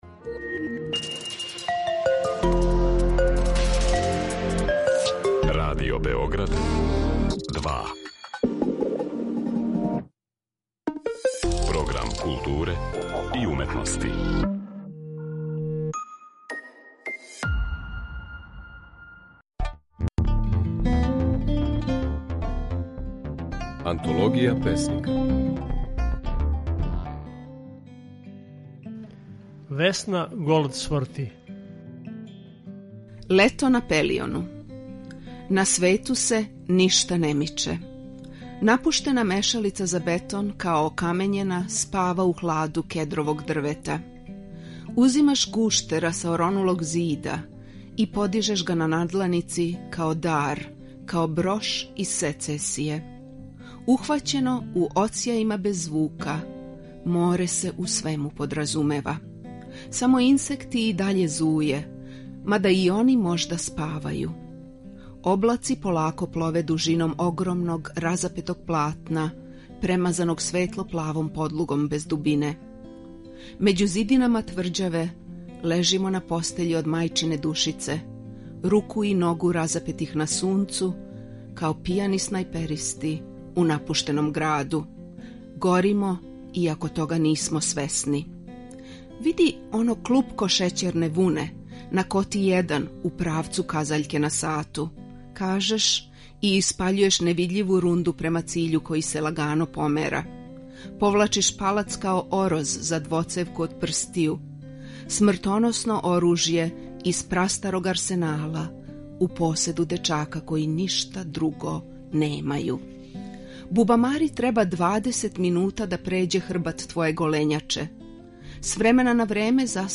У данашњој емисији, можете чути како своје стихове говори песникиња Весна Голдсворти.